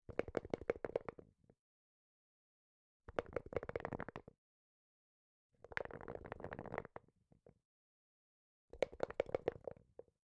骰子的声音（雅致的风格）" 骰子（1）在杯中摇动，关闭
描述：用森海塞尔MKH 416（据我记得）通过Focusrite Scarlett 2i2录入Cubase 6.5，48kHz 24 bit。
Tag: 骰子 骰子 游戏 模具 拟音